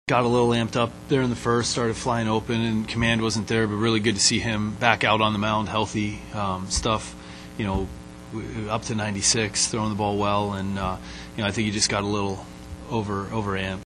Kelly added that that starting pitcher Johan Oviedo may have been too amped up for his appearance Monday night, and that may have lead him to not being as sharp as he normally is.